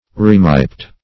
Remiped \Rem"i*ped\, n. (Zool.)